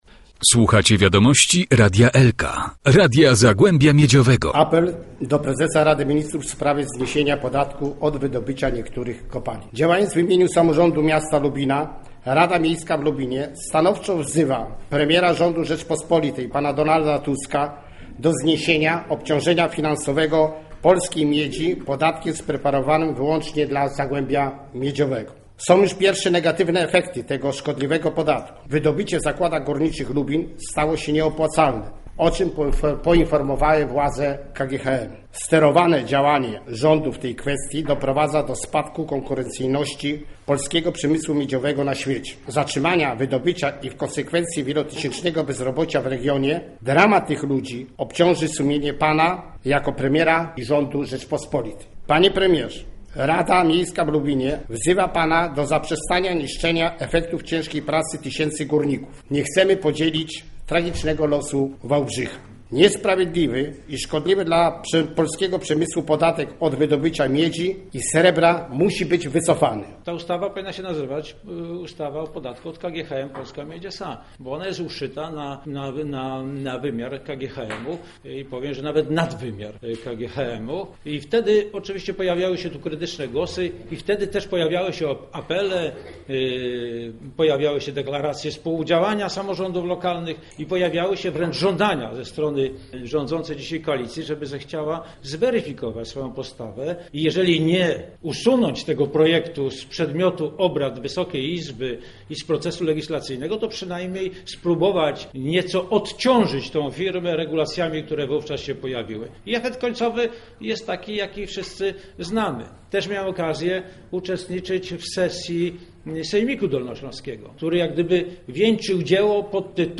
Tak licznej i zacnej widowni dawno nie było podczas dzisiejszej nadzwyczajnej sesji Rady Miejskiej w Lubinie.
Apel do premiera rządu Donalda Tuska (czyta radny Marian Węgrzynowski). Komentarze polityczne: poseł Ryszard Zbrzyzny i posłanka Elżbieta Witek. Ocena prezydenta Lubina, Roberta Raczyńskiego: